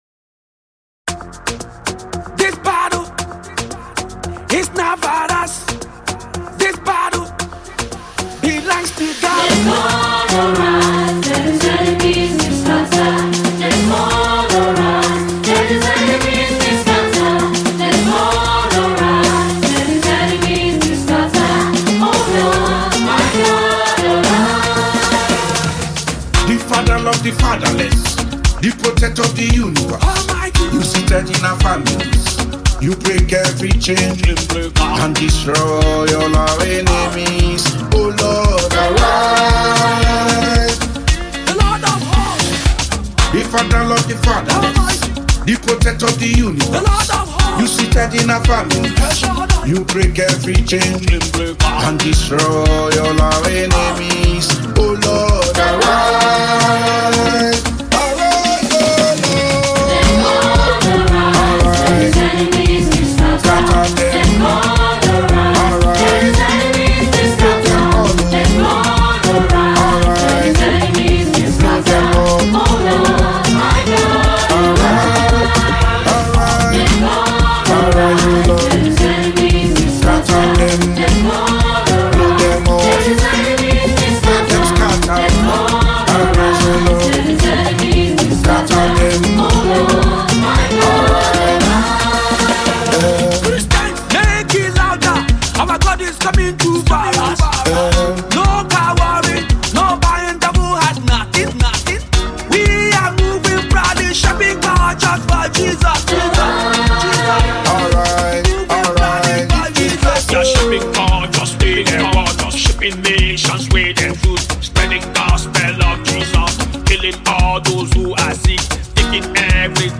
powerful gospel single